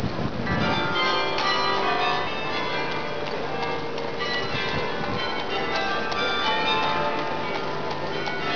運河と石畳の町でマルクト広場の教会からはカリオンの音が響く。
カリオン：４０〜５０個の音の違う鐘を鳴らして音楽を奏でるもの。単にゴーン、ゴーンと鳴る教会の鐘とは違いオルゴールのよう。